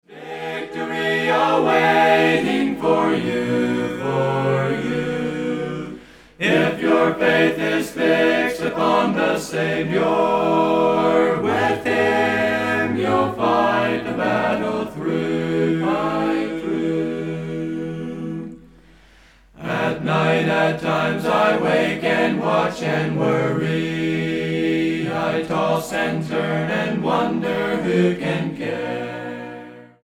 harmonizes well together